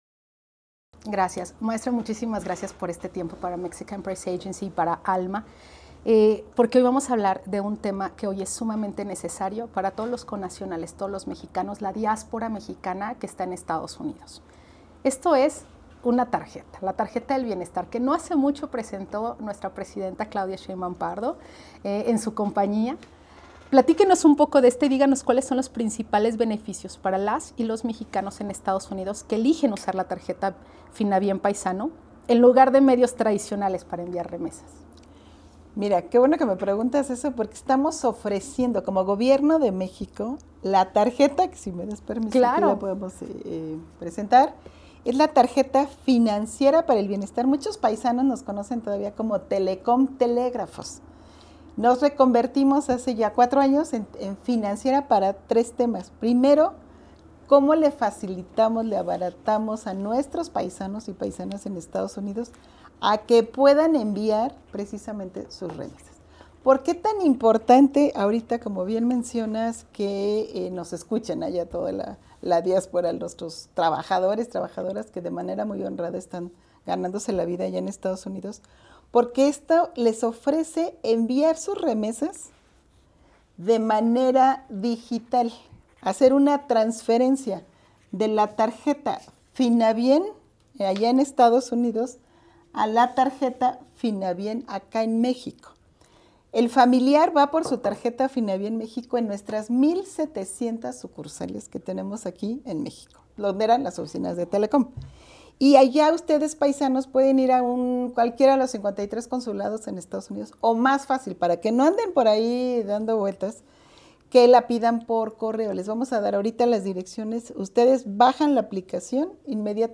En entrevista con Mexican Press Agency, la directora general de la Financiera para el Bienestar (Finabien), Rocío Mejía Flores, explicó que la tarjeta representa mucho más que un medio de transferencia.
Entrevista-Rocio-Mejia-Finabien.mp3